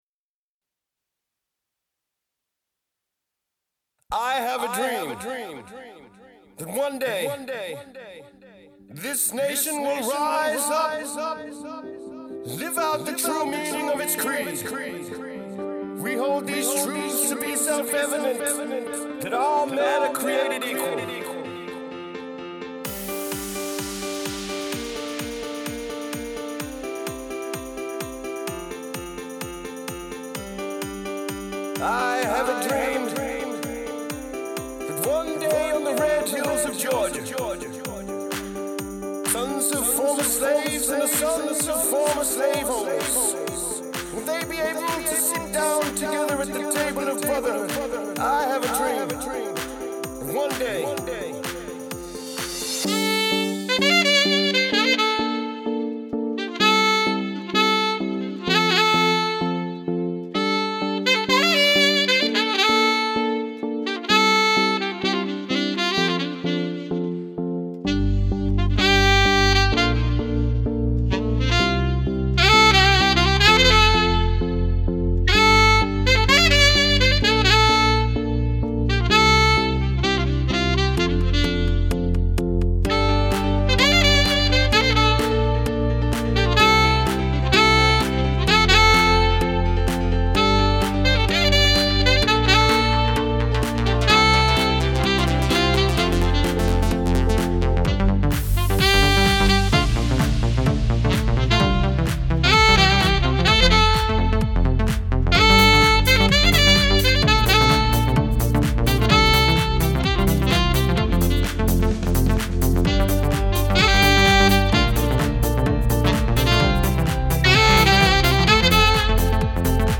Ibiza sound